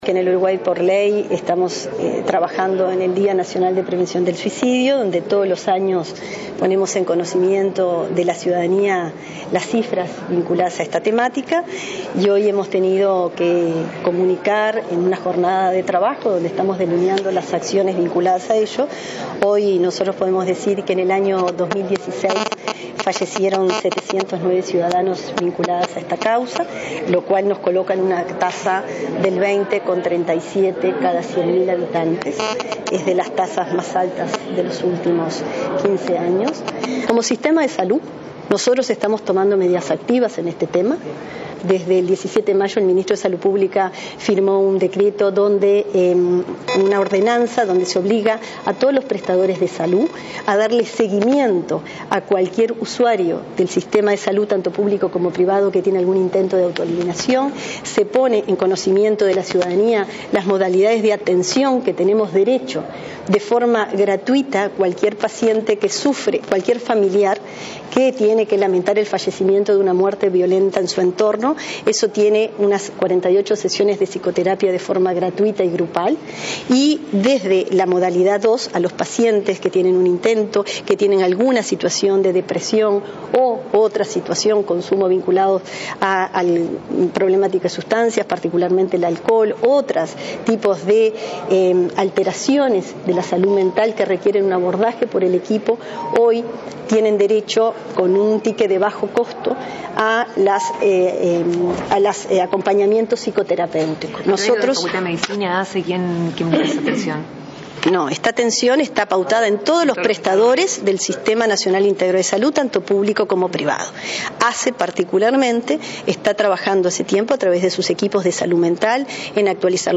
Prestadores de salud pública y privada deben brindar acompañamiento a pacientes con conductas suicidas, eliminar tiempos de espera prolongada tras la primera consulta, otorgar tickets gratuitos a pacientes afectados por muerte violenta en su entorno y atención psicoterapéutica a bajo costo a personas con alteraciones de salud mental, dijo la subsecretaria Cristina Lustemberg, en el Día Nacional de Prevención del Suicidio.